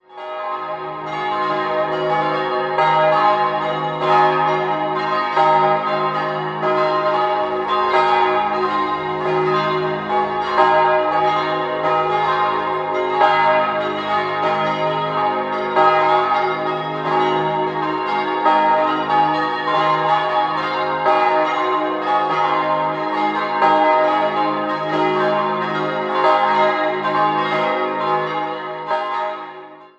5-stimmiges Geläute: es'-as'-c''-es''-as''
bell
Die Dreifaltigkeitsglocke ist mit einem Reversionsklöppel und Gegengewichten auf dem Joch ausgestattet, was einen langsameren Läuterhythmus zur Folge hat.